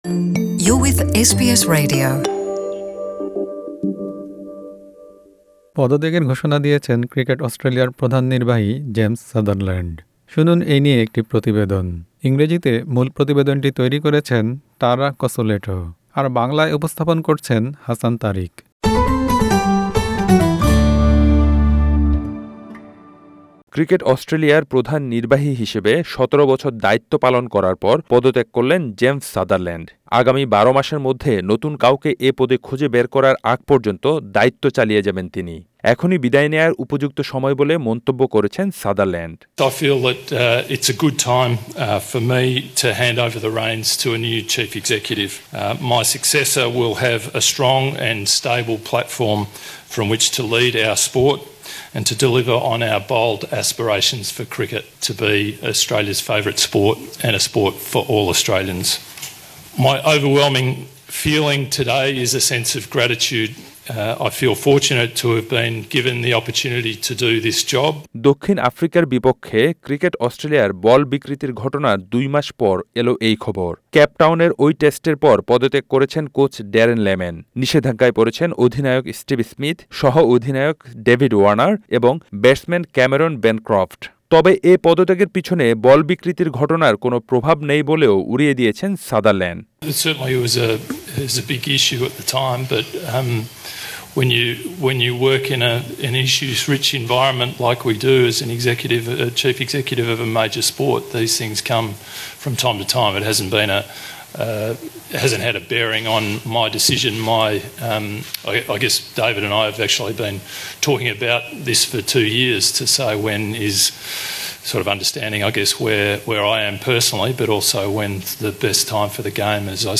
Click the audio player to listen to the full report in Bangla.